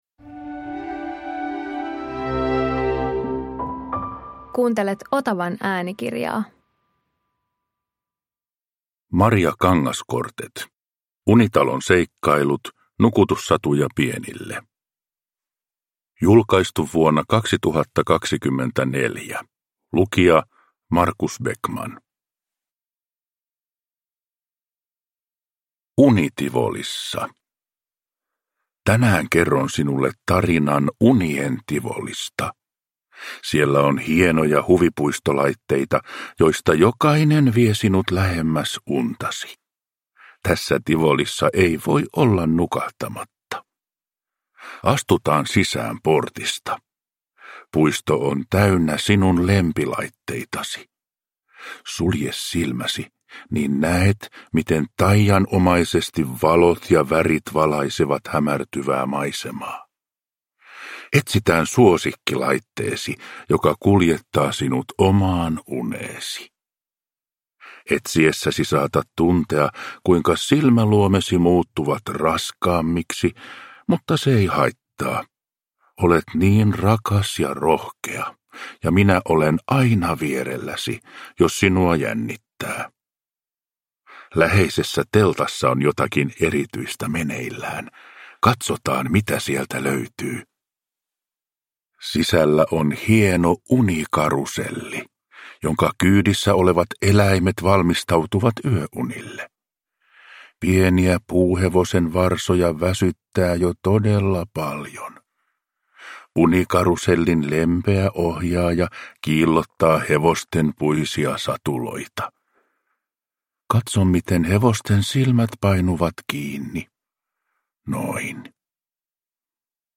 Unitalon seikkailut – Ljudbok